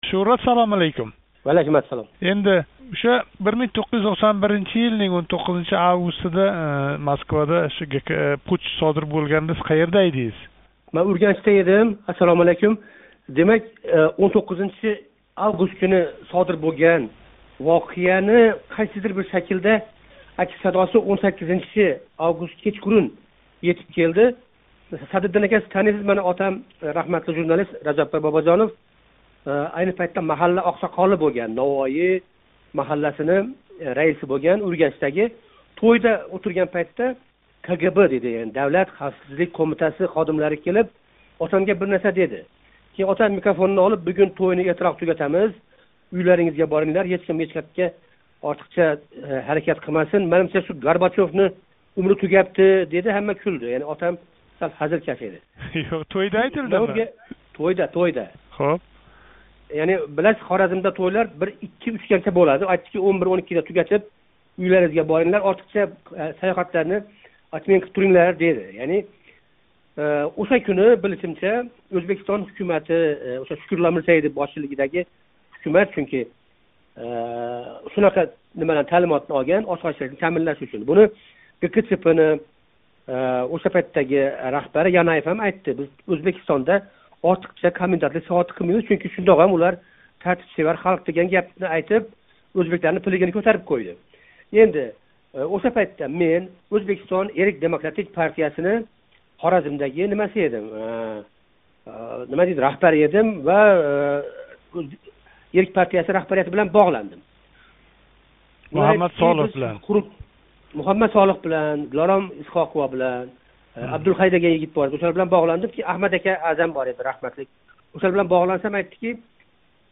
Журналист ва рассом